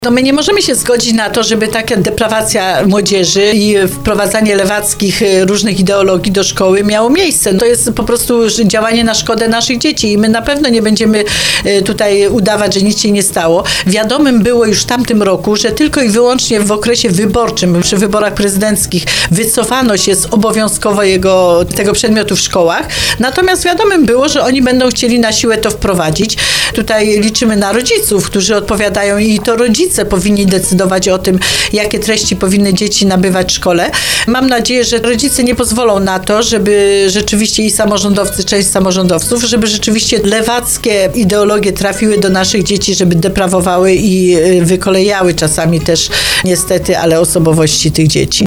Tymczasem poseł PiS Józefa Szczurek-Żelazko w programie Słowo za Słowo na antenie RDN Małopolska podkreśliła, że jej ugrupowanie nie poprze takich zmian.